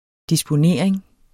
Udtale [ disboˈneɐ̯ˀeŋ ]